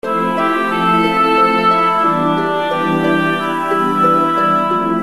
オール耳コピ＆少しだけアレンジしています。
試聴する 5秒間クオリティーを少し下げたものを聞けます。（サイズ60KB）